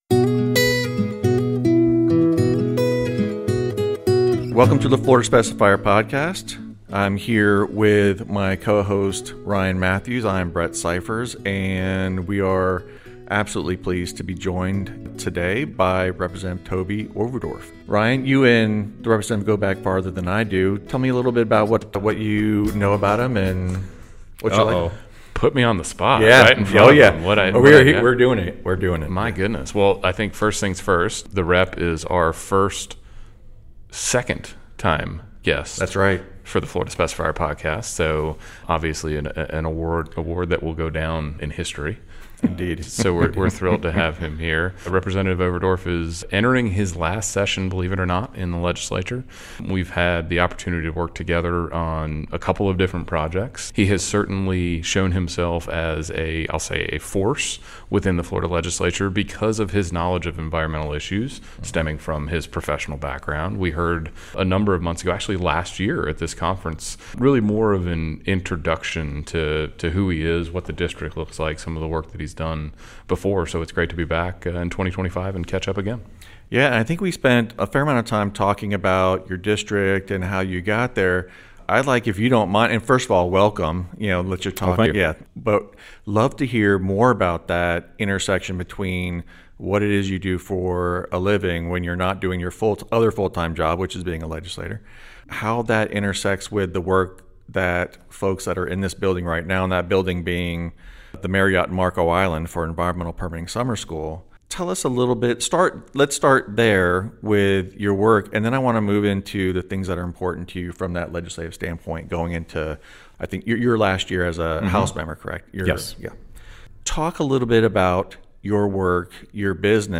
Recorded on-site at the 39th Annual Environmental Permitting Summer School, the conversation explores how Representative Overdorf’s background continues to shape his legislative priorities. From new stormwater regulations and the rising cost of environmental restoration, to his thoughts on water supply, infrastructure needs, and invasive species management, Representative Overdorf offers a candid look at what it takes to balance Florida’s rapid growth with environmental protection.